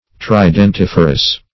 Search Result for " tridentiferous" : The Collaborative International Dictionary of English v.0.48: Tridentiferous \Tri`dent*if"er*ous\, a. [L. tridentifer; tridens trident + ferre to bear.] Bearing a trident.